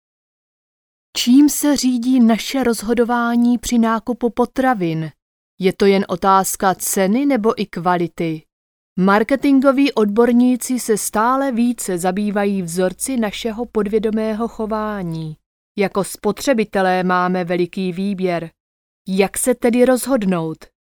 Czech Voice Over
You can receive Czech Voice Over service from our professional Czech Voice Over Artists whose mother tongue is Czech.
FEMALE